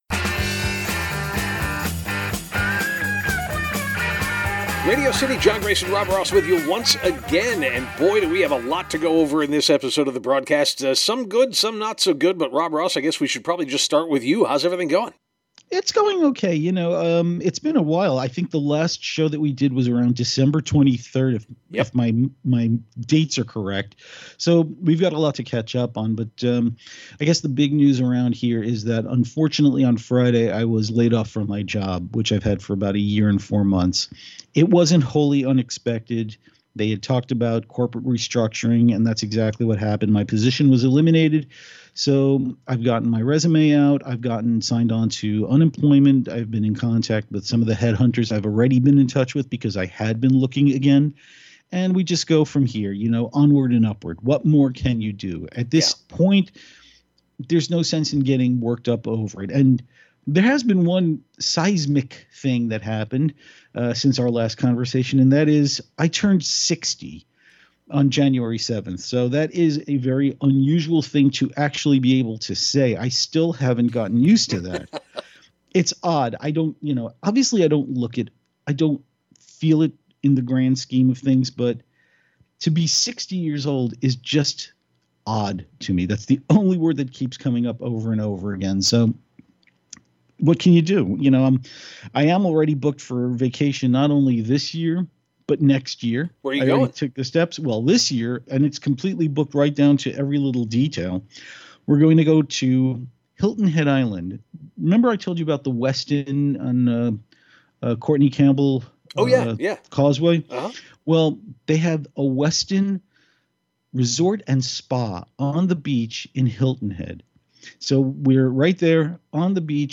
It’s quite an interesting and thoughtful conversation